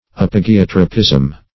Search Result for " apogeotropism" : The Collaborative International Dictionary of English v.0.48: Apogeotropism \Ap"o*ge*ot"ro*pism\, n. The apogeotropic tendency of some leaves, and other parts.